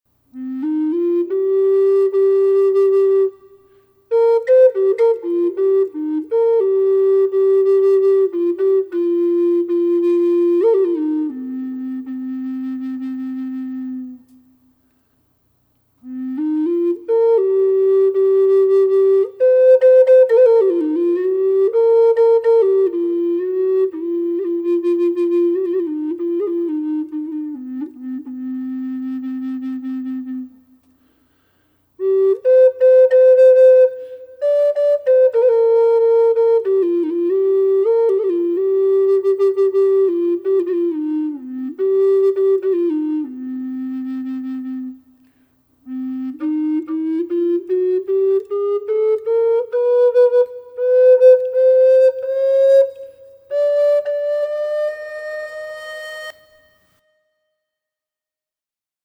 Sound sample of  this Flute short melody + 16 notes scale  with a light reverb
LOW-Cm-Reverb.mp3